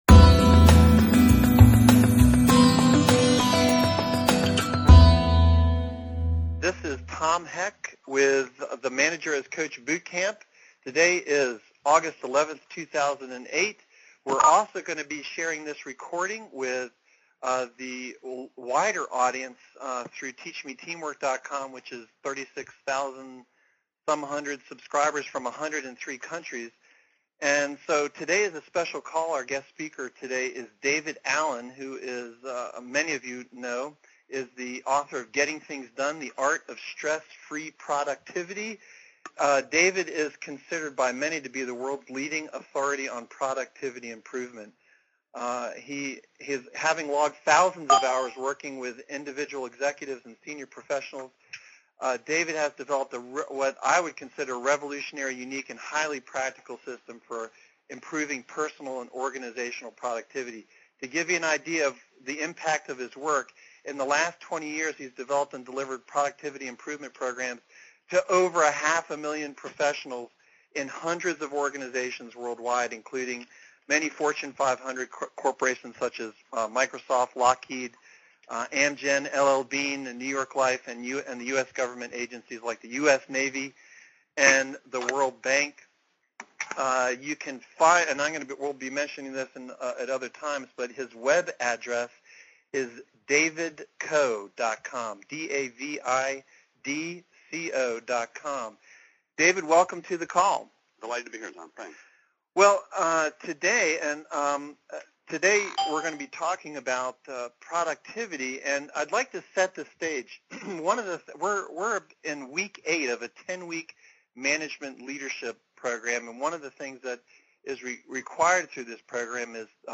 How to get things done — Productivity guru David Allen audio interview shares strategies to increase productivity and reduce stress – TeachMeTeamwork